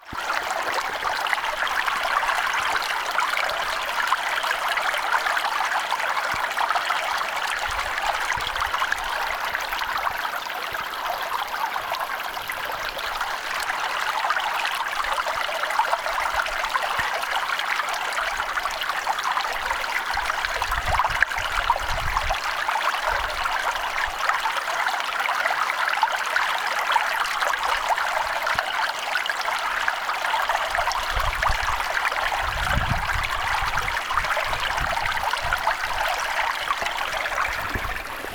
vesi solisee joulukuun ensimmäisenä
vanhan lintutornin luona
vesi_solisee_joulukuun_ensimmaisena_vanhan_lintutornin_luona.mp3